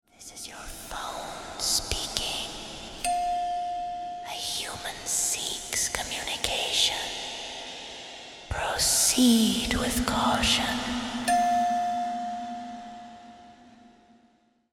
Halloween Scary Horror Haunted Whisper